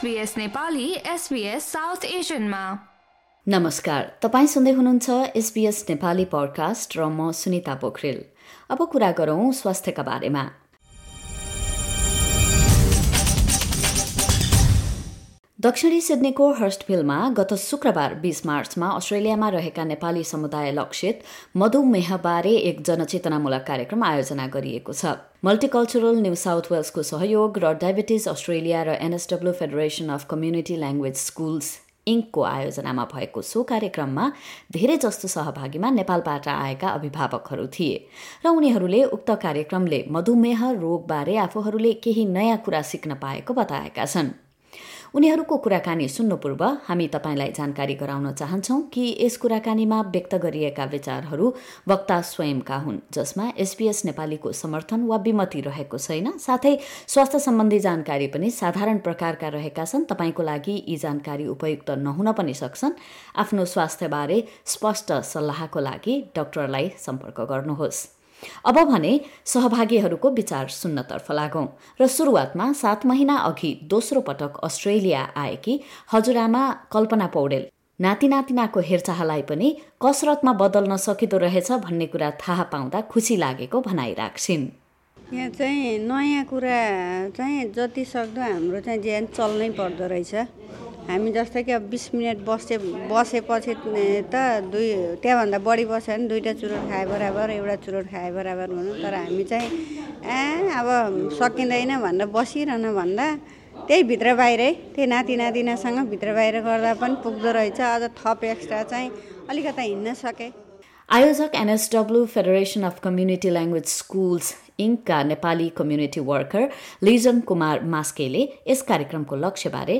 Visiting Nepali parents say they were encouraged to learn that diabetes can be effectively managed through regular physical activity and healthy eating. A diabetes awareness program for the Nepali-speaking community was held in Sydney on Friday, 20 March, organised by Diabetes Australia and the NSW Federation of Community Language Schools Inc, with support from Multicultural New South Wales. SBS Nepali spoke with participants and organisers about the program and its outcomes.